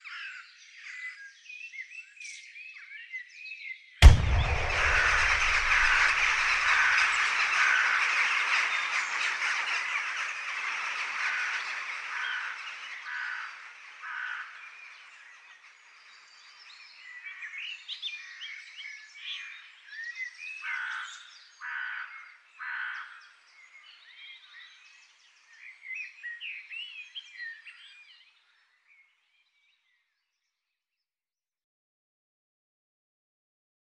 Звуки тревоги: выстрел, затем птицы в панике разлетаются